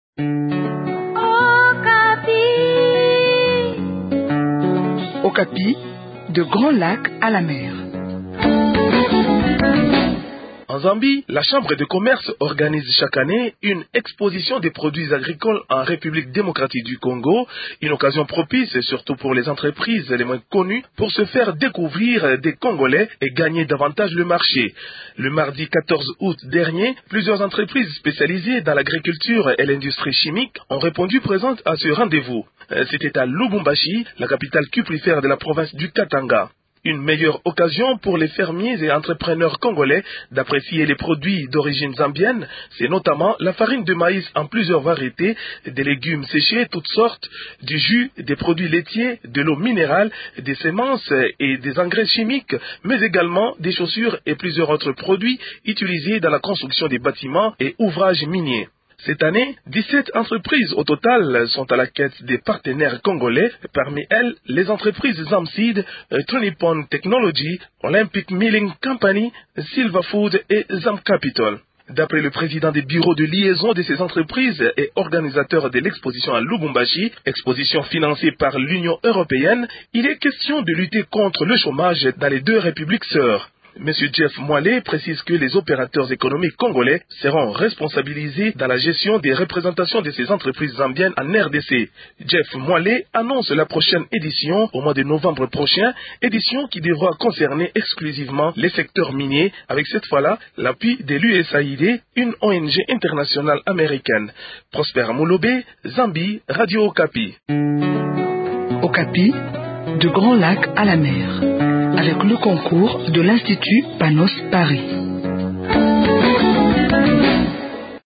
dans cette chronique des grands lacs à la mer.